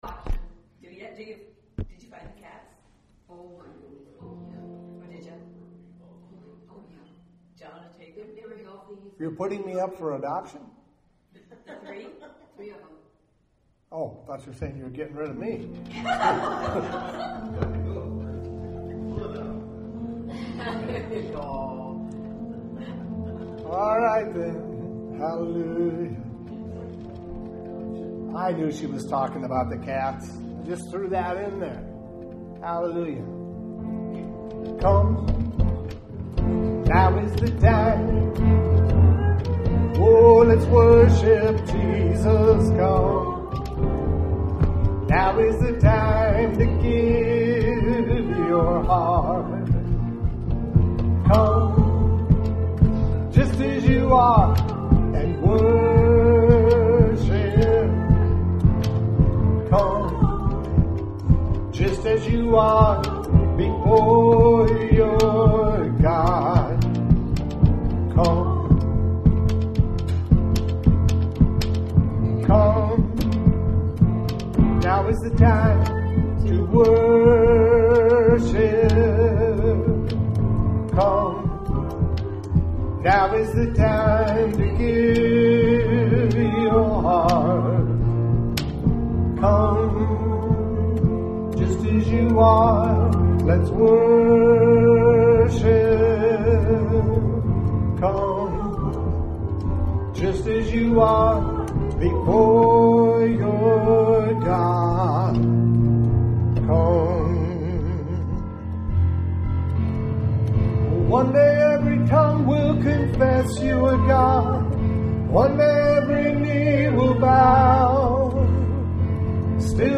WORSHIP 615.mp3